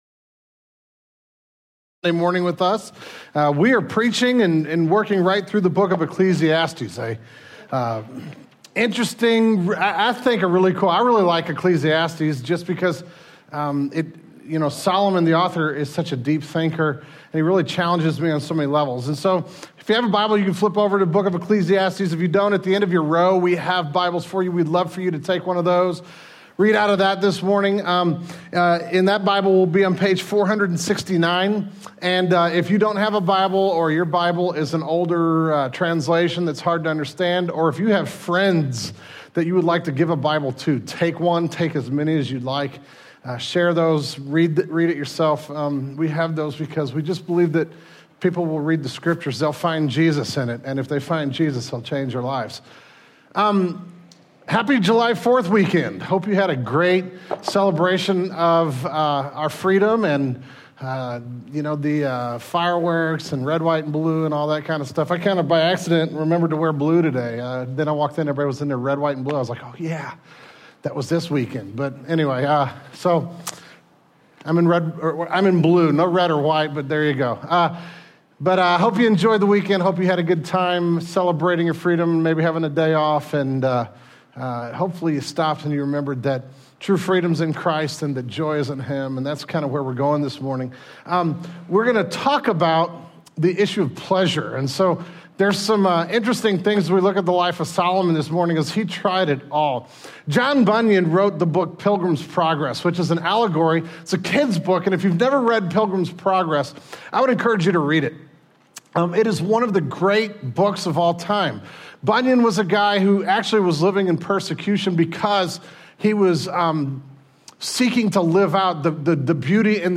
The July 2008 Sermon Audio archive of Genesis Church.